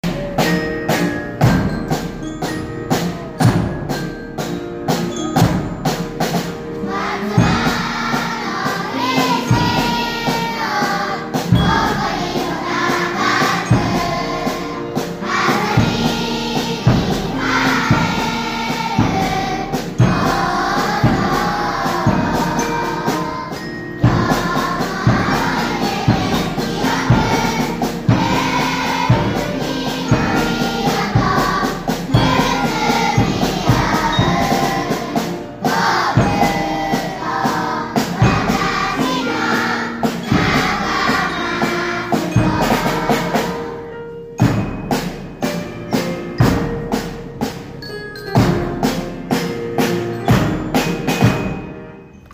0922♪全校朝会♪校歌♪